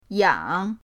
yang3.mp3